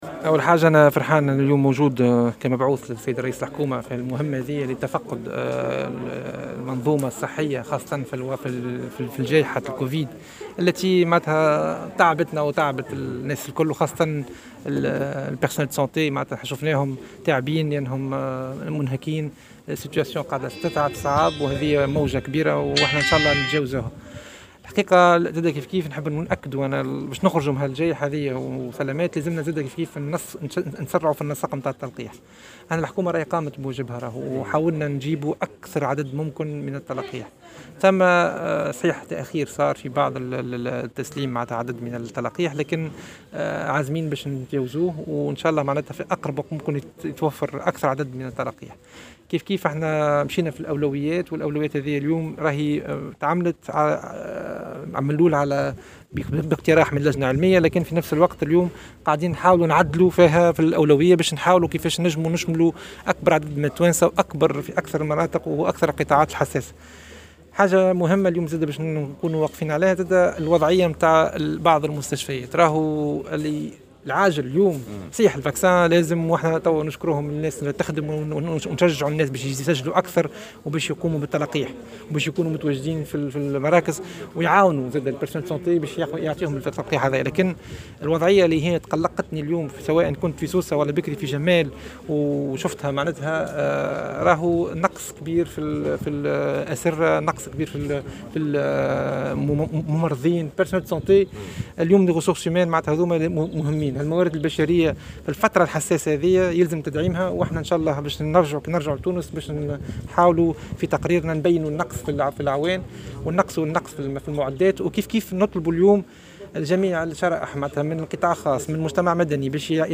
قال وزير النقل واللوجستيك، معز شقشوق، في تصريح لمراسل الجوهرة أف أم، على هامش زيارة أداها اليوم الجمعة إلى ولاية المنستير، إن الحكومة قد قامت بواجبها في ما يتعلق باللقاح المضاد لفيروس كورونا المستجد، وحاولت استجلاب أكبر عدد ممكن من الجرعات، رغم التأخير الحاصل في عملية التسليم.